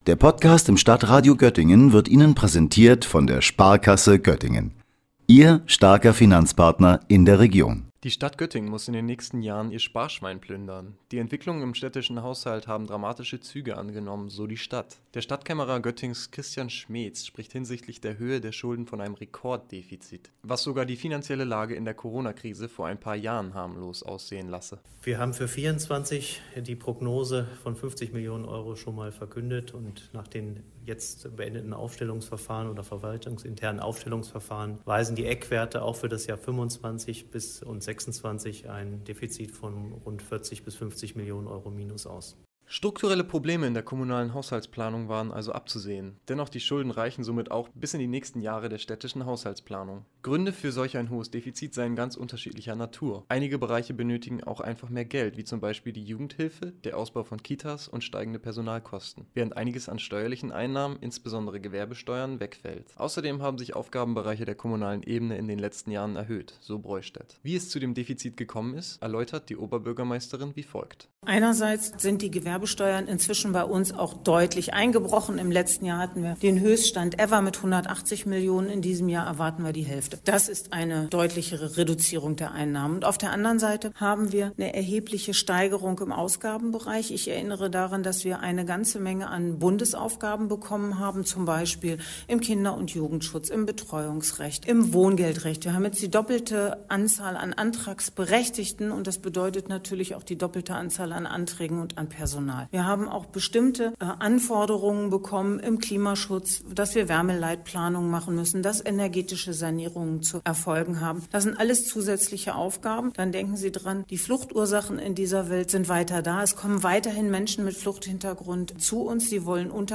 Nun hat sich im Vorfeld der Berechnungen ergeben, dass es Göttingen dieses Jahr finanziell schwerer trifft als zuvor, dies zieht sich auch in die nächsten Planungsjahre. Oberbürgermeisterin Broistedt und Stadtkämmerer Schmetz erläutern den Haushalt.